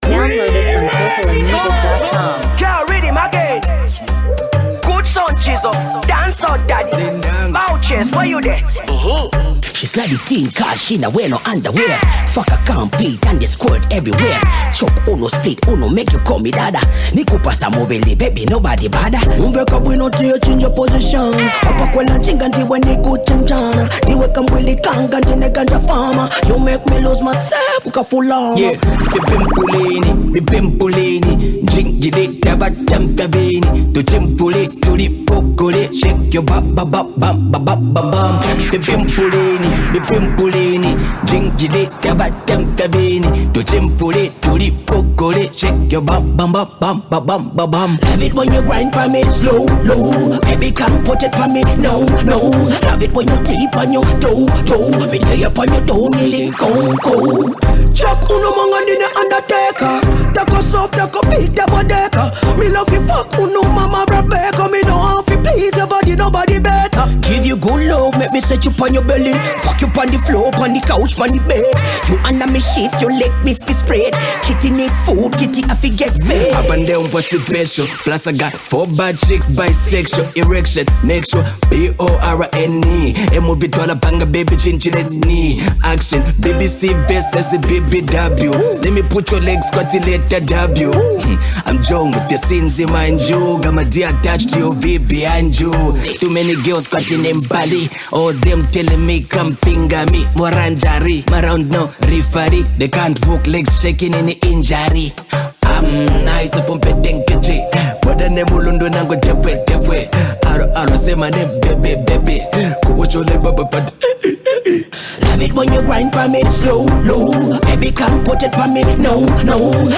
MusicZambian Music